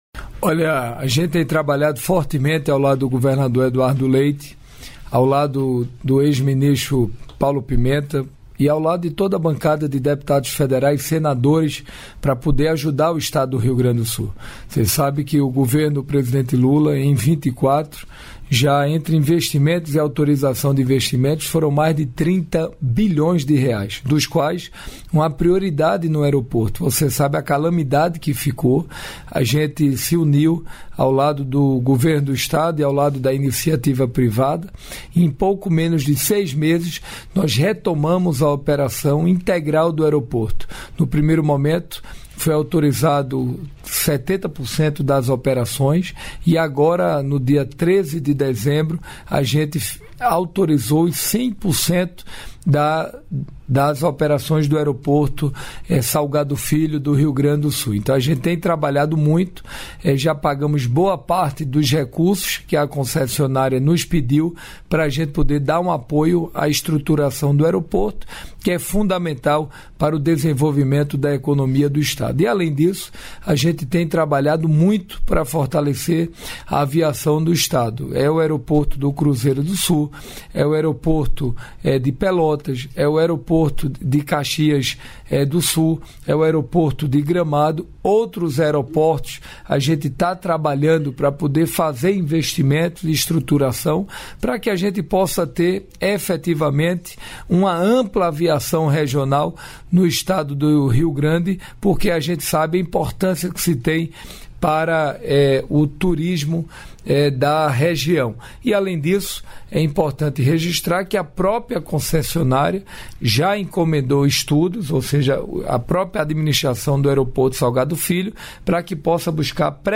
Trecho da participação do ministro de Portos e Aeroportos, Silvio Costa Filho, no programa "Bom Dia, Ministro" desta quinta-feira (06), nos estúdios da EBC, em Brasília.